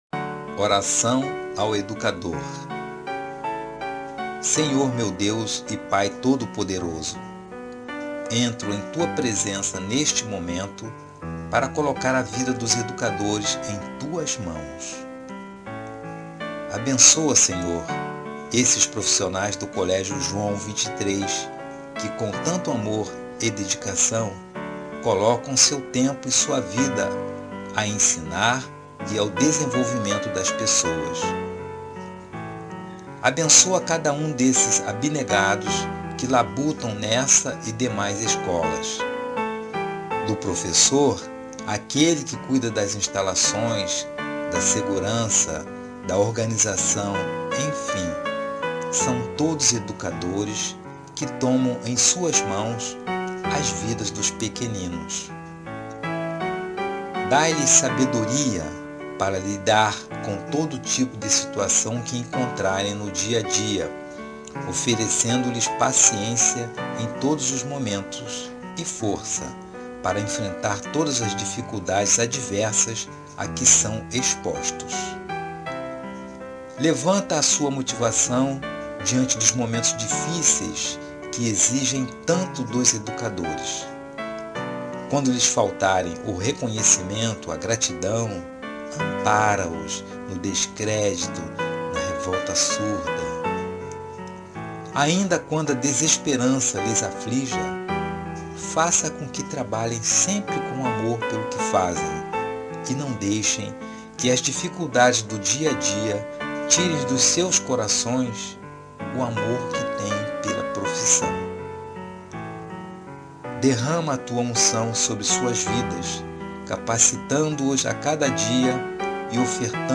Oração do Colégio